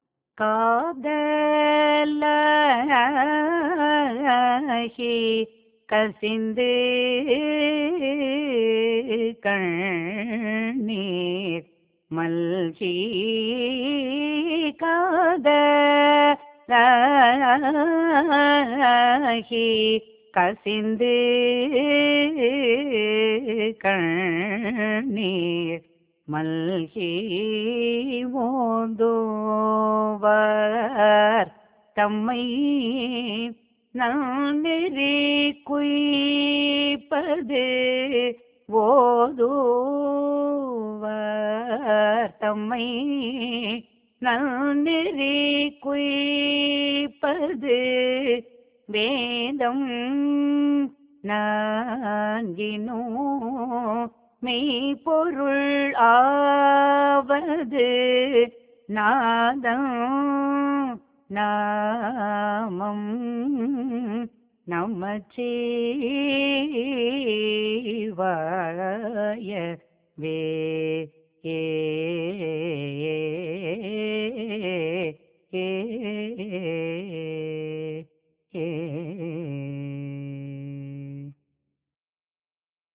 பண் : கௌசிகம்